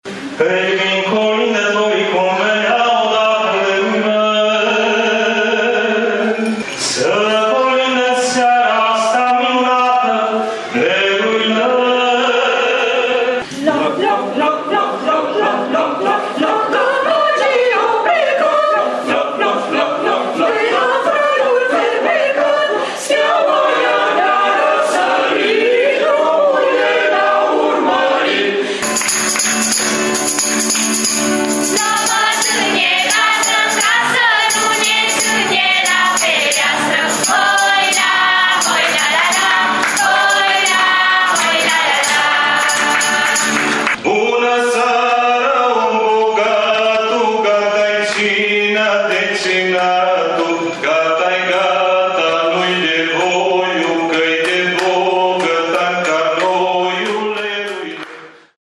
La Parohia nr 1 greco-catolică din Tîrgu-Mureș a avut loc aseară un regal de peste 3 ore de colinde în cadrul celei de-a XII-a ediții a Concertului interconfesional de colinde.